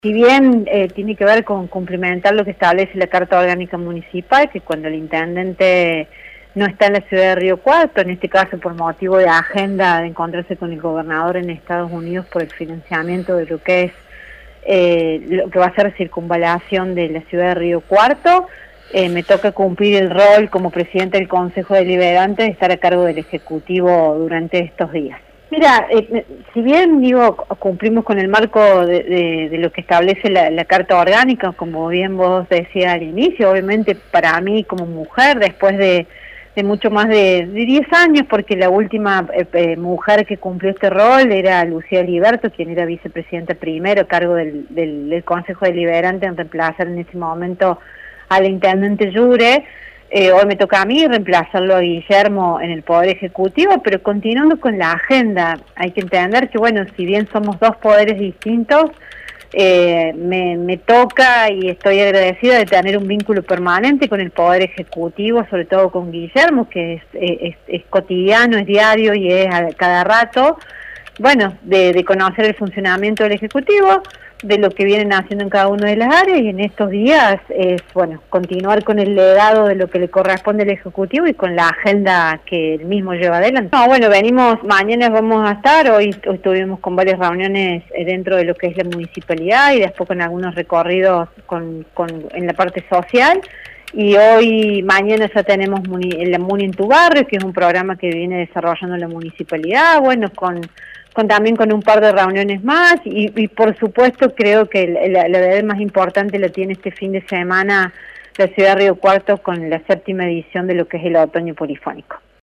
En diálogo con Universidad, Ana Medina dijo que continuarán con la agenda del ejecutivo.